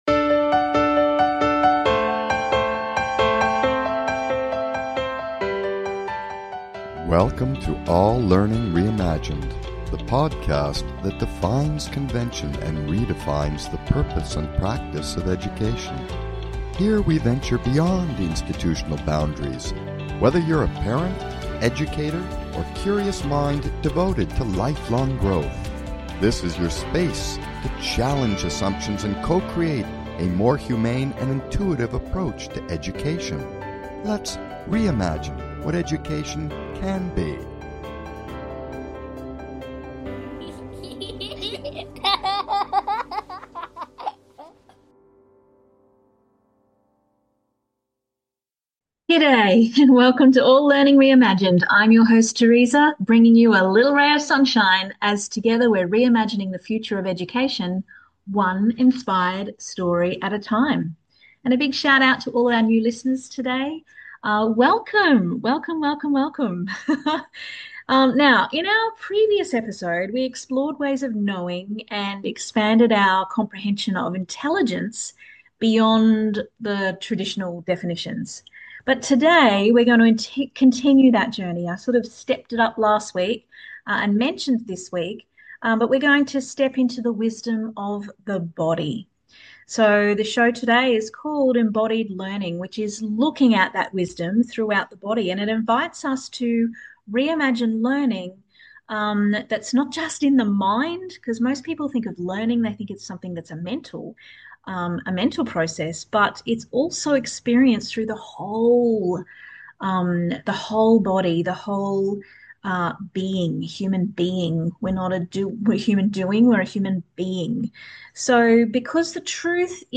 Talk Show Episode, Audio Podcast, All Learning Reimagined and Embodying learning - Wisdom through the body on , show guests , about Embodying learning,Wisdom Through the Body,Experience as the Glue of Memory,Walk and Talk,Natural Materials,Body Pause,Concept Acting,Safety,the Nervous System,Sensory Integration, categorized as Arts,Education,Health & Lifestyle,Kids & Family,Philosophy,Psychology,Science,Self Help,Society and Culture